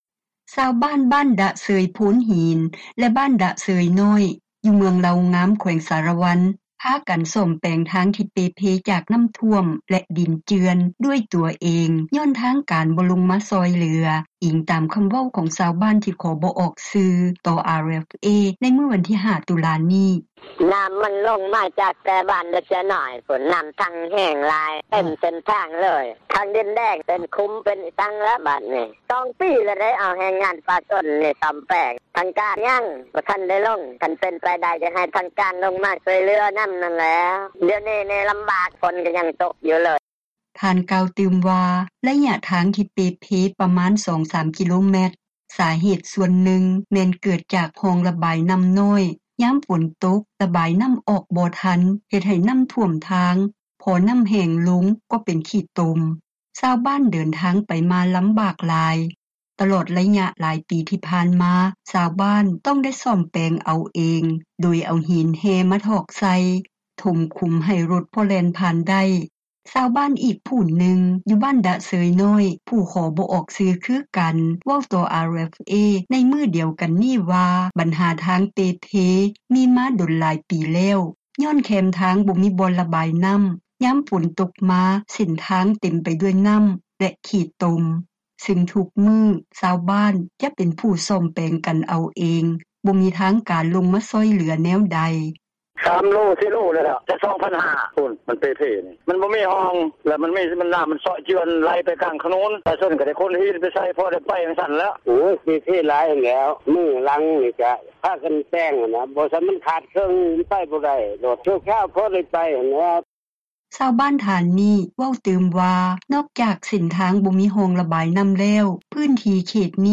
ຊາວບ້ານອອກແຮງອອກເງິນ ແປງທາງ – ຂ່າວລາວ ວິທຍຸເອເຊັຽເສຣີ ພາສາລາວ